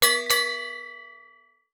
Boxing Bell Fight Start.wav